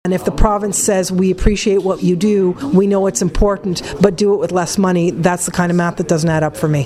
You’re listening to a voice stilled before its time, Carleton Place councillor and former journalist Theresa Fritz, whose untimely passing in January interrupted her legacy of public service.